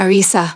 synthetic-wakewords
ovos-tts-plugin-deepponies_Naoto Shirogane_en.wav